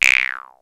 VARIOUS FILT 2.wav